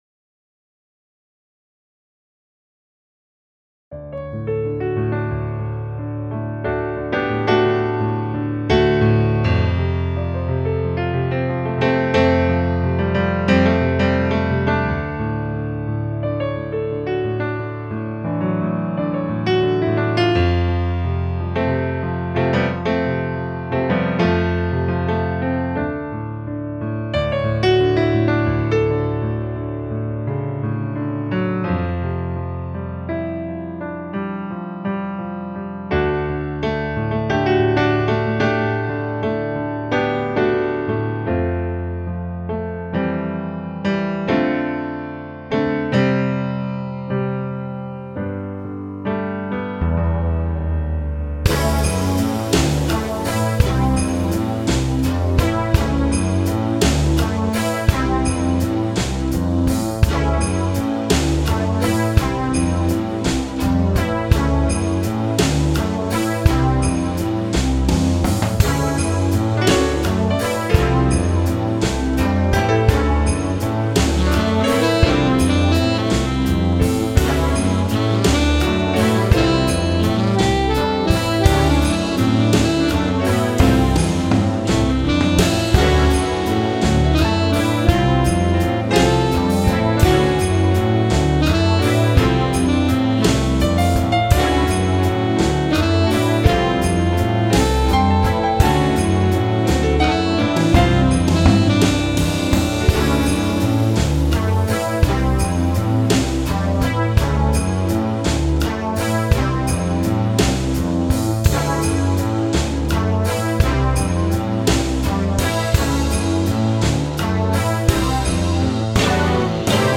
Das erste größere Werk auf dem Kurzweil-Samplekeyboard. Entstanden Anfang der 2000er in Hepsisau, ausgearbeitet und mit Piano-Intro veredelt 2010 in Wernau.
Piano